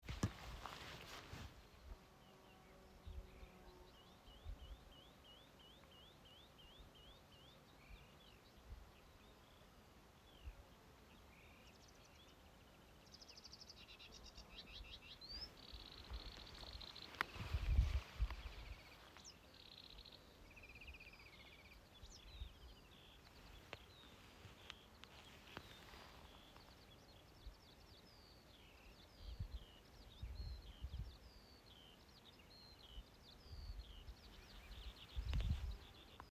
Yellow Wagtail, Motacilla flava
StatusVoice, calls heard